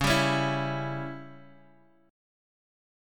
C#dim chord